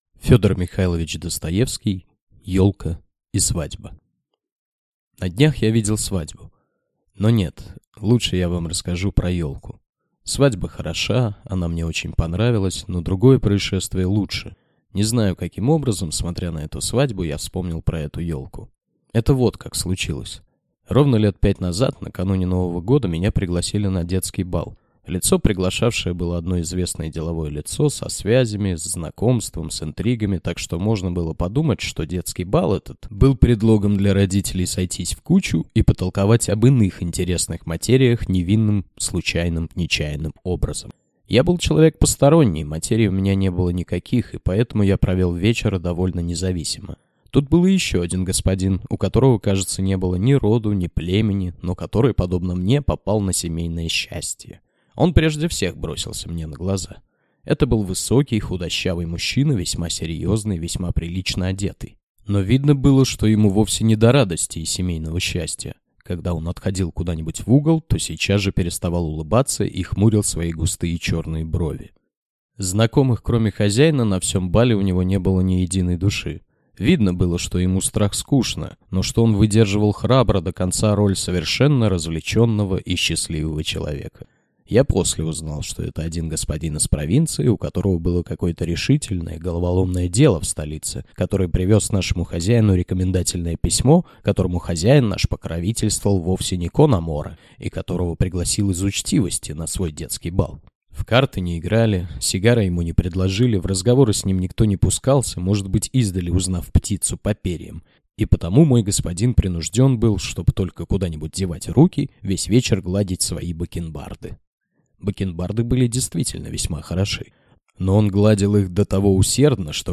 Аудиокнига Елка и свадьба | Библиотека аудиокниг